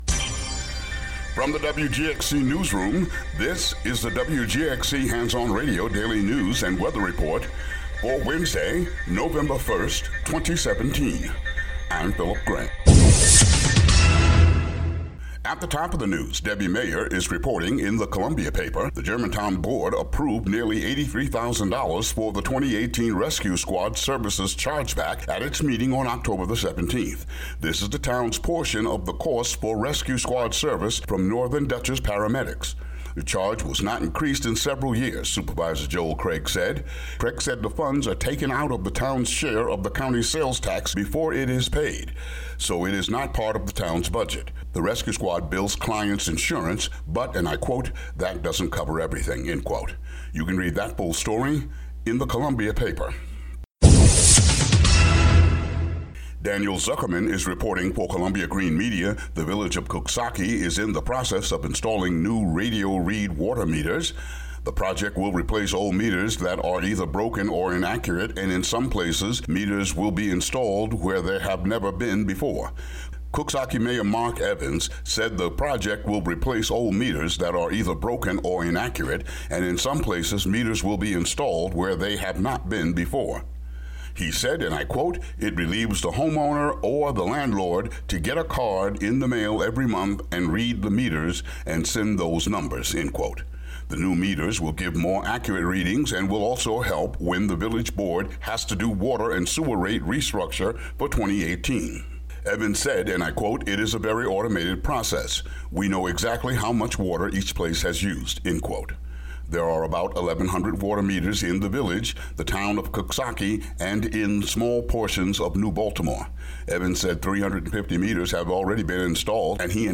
WGXC daily headlines for Nov. 1, 2017.